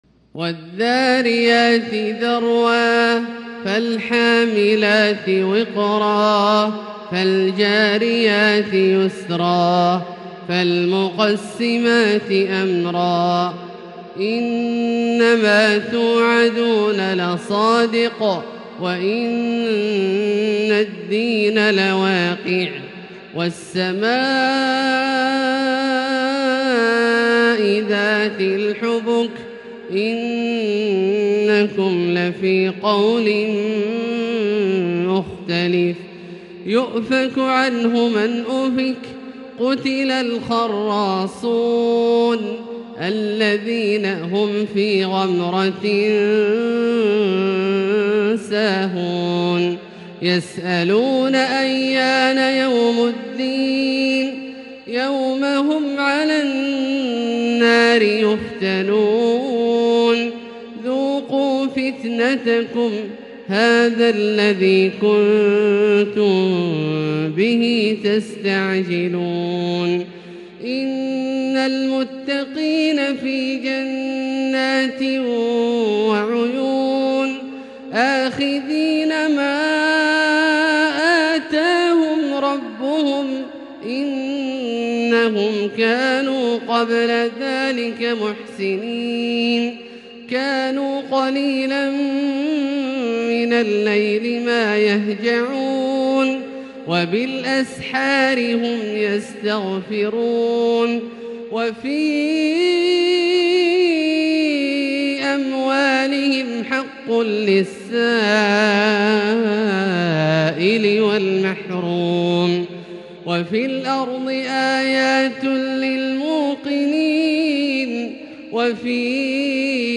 تلاوة جميلة لـ سورة الذاريات كاملة للشيخ د. عبدالله الجهني من المسجد الحرام | Surat Adh-Dhariyat > تصوير مرئي للسور الكاملة من المسجد الحرام 🕋 > المزيد - تلاوات عبدالله الجهني